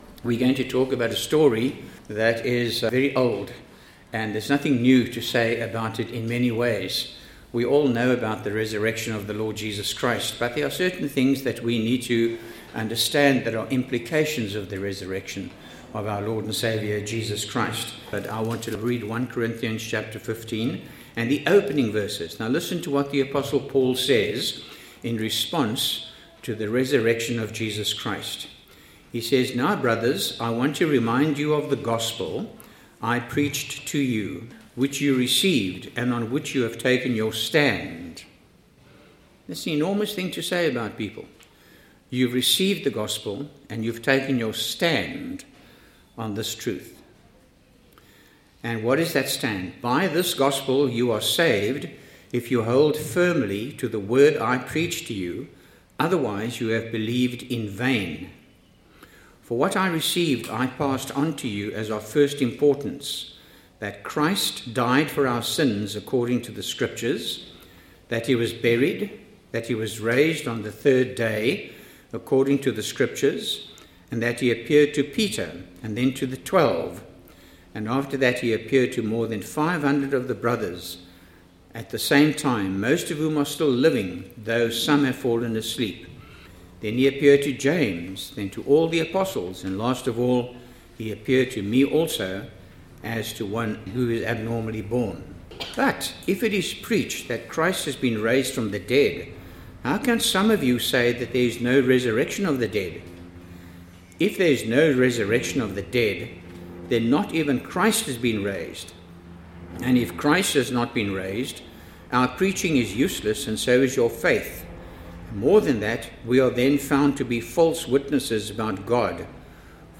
Radio Cross Examined Easter Sunday 2019.mp3